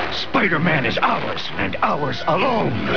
From the Spider-Man animated series.